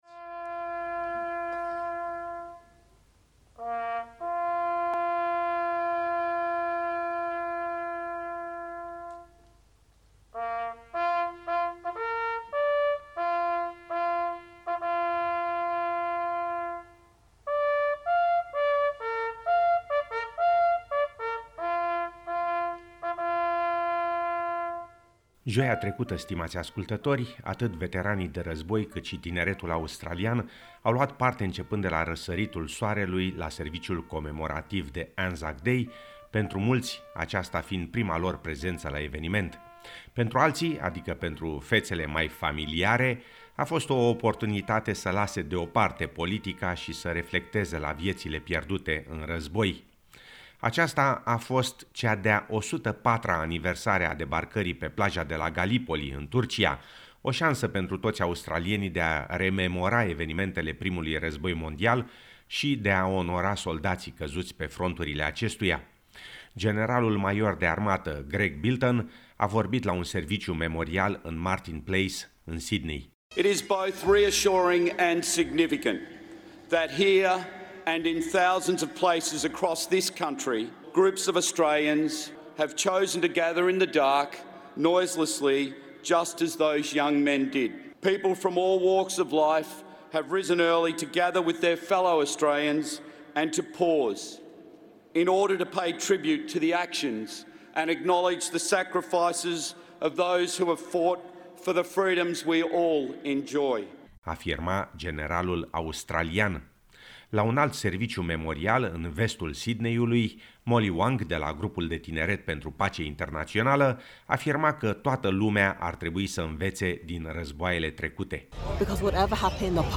crowds_gather_at_anzac_day_dawn_services_final_-_6.10.mp3